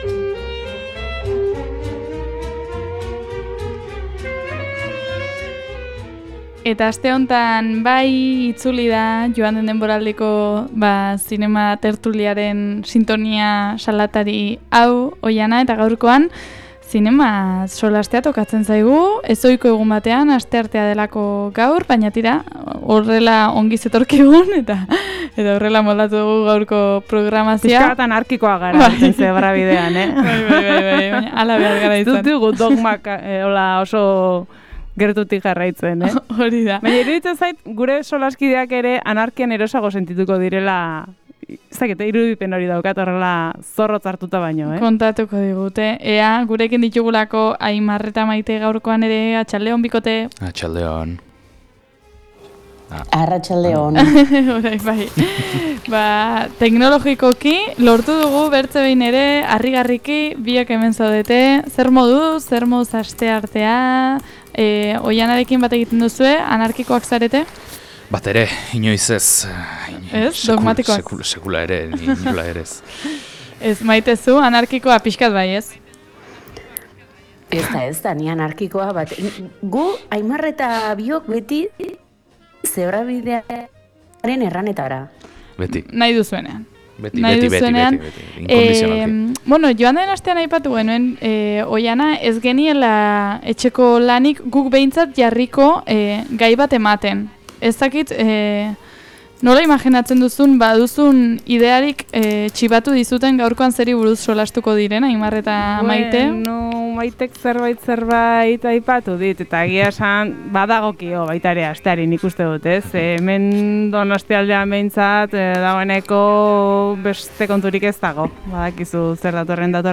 Donostiako Zinemaldirako motorrak berotu nahi? · Zine tertulia 09.17